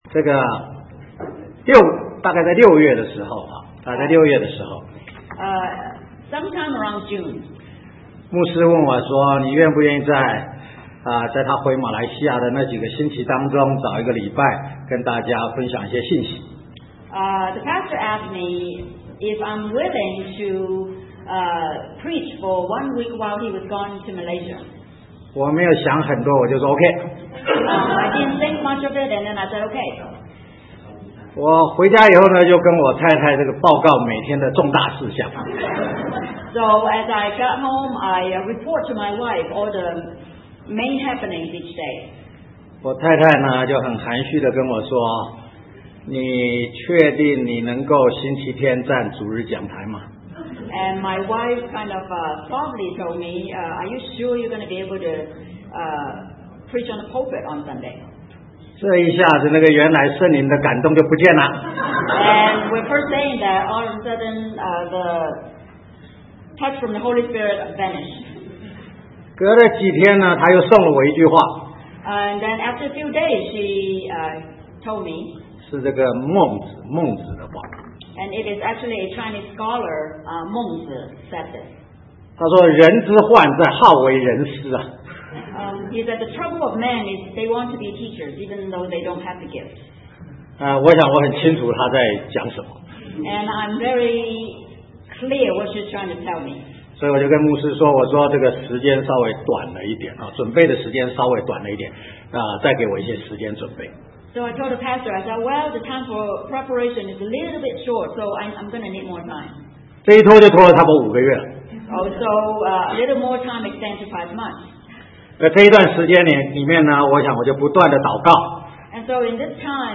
Sermon 2009-11-08 The Cost of Faith